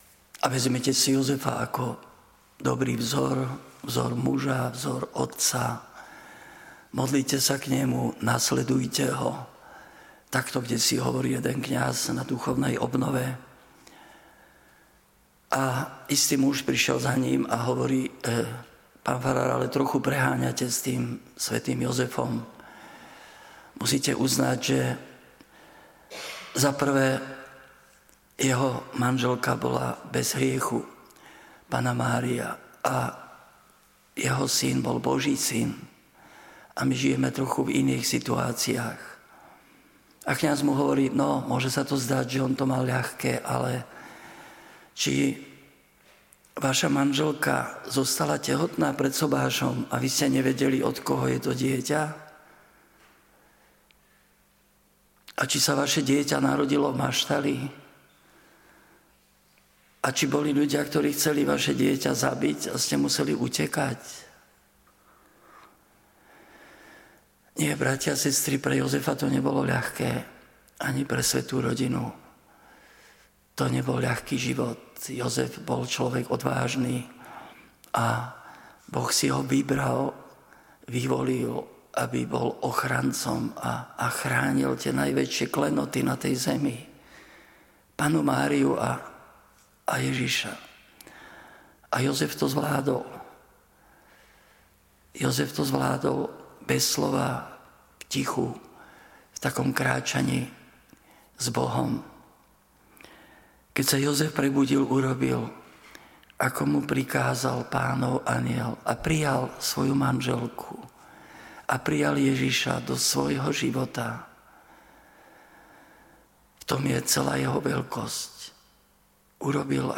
svätého Jozefa, ženícha preblahoslavenej Panny Márie
Podcasty Kázne JOZEF BOL ČLOVEK ODVÁŽNY